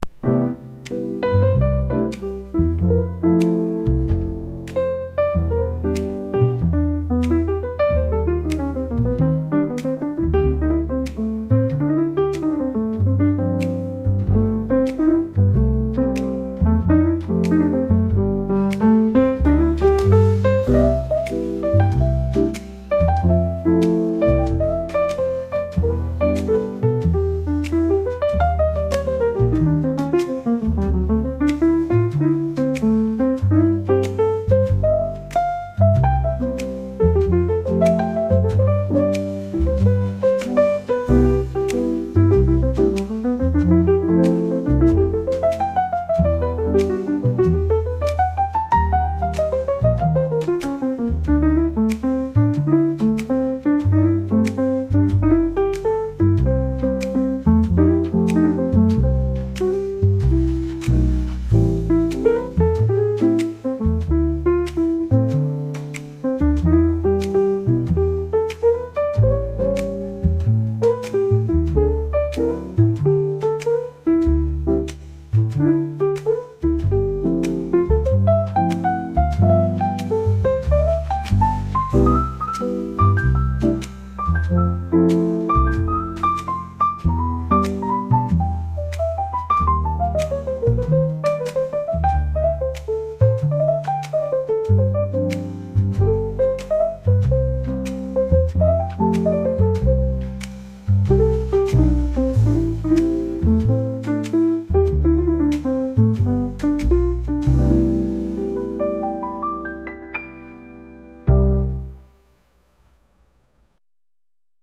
カフェで音楽がうるさすぎない雰囲気のジャズ音楽です。